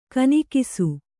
♪ kanikisu